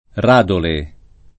[ r # dole ]